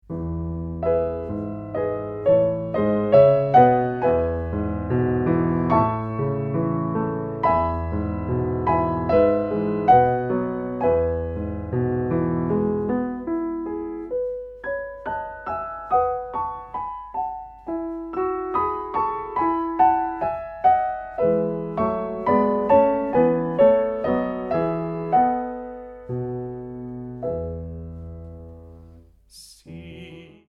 Dúó